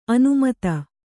♪ anumata